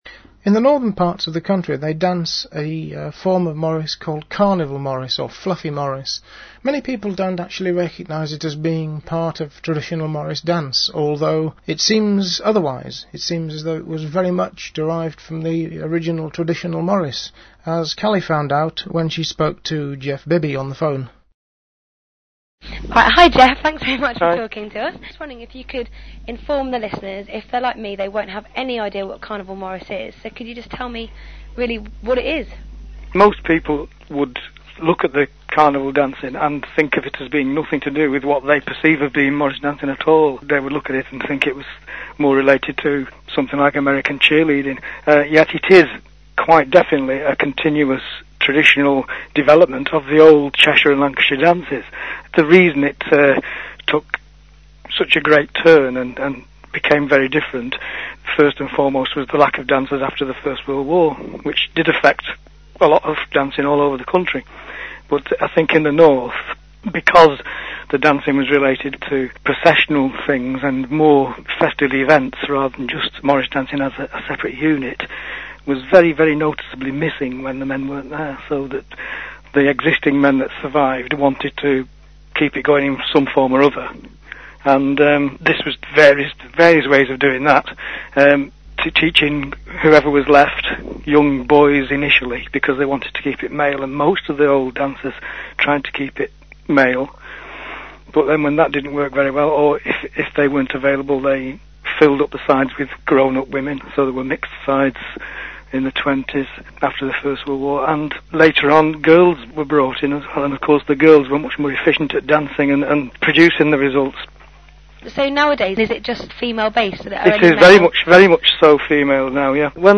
This is an extract from the radio programme "Morris Circles".